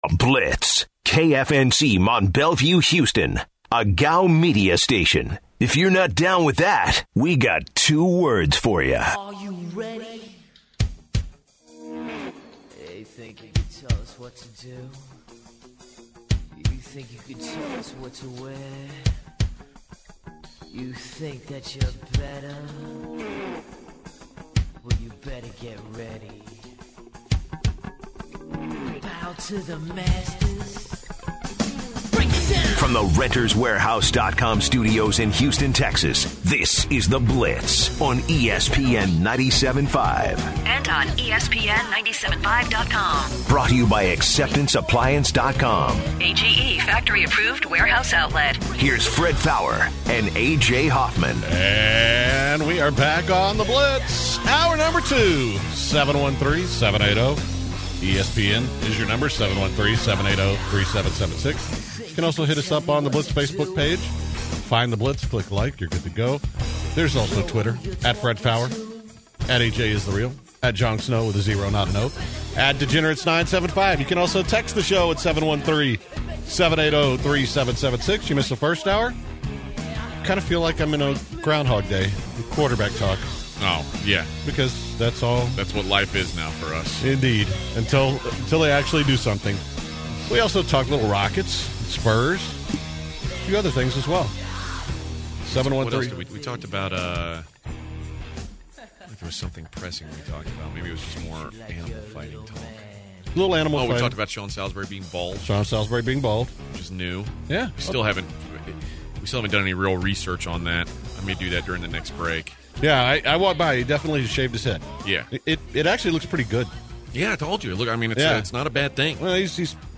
In hour two, callers call in to weigh in on Jimmy Garoppolo, Brandin Cooks, and what QB will fit the Texans system.